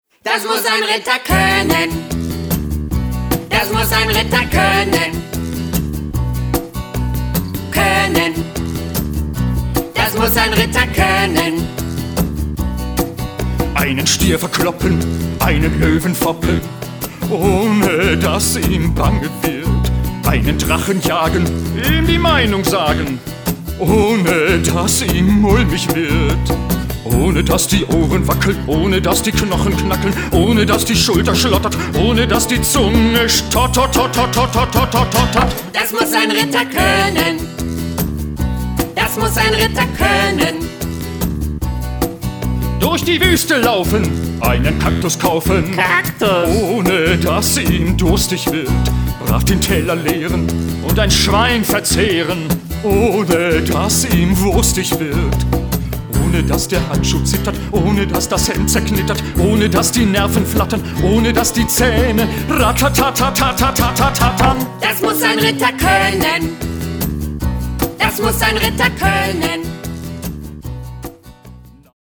Eine CD mit allen Liedern und der Geschichte zum Anhören